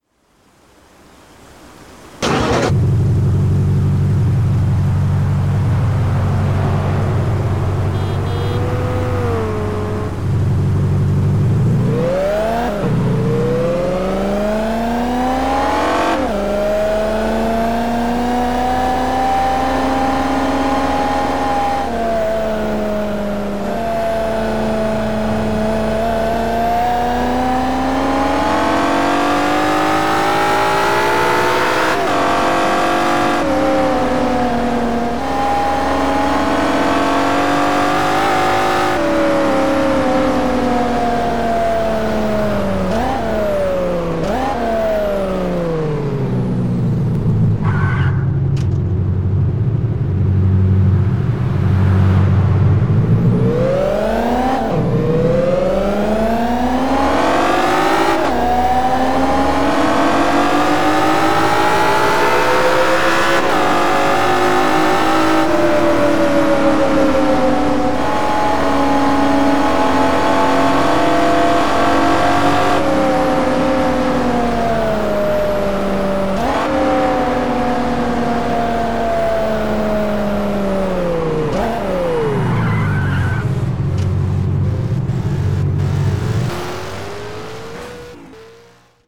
- BMW M3 [E92] [Bassy]